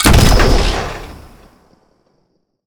sci-fi_weapon_rifle_large_shot_05.wav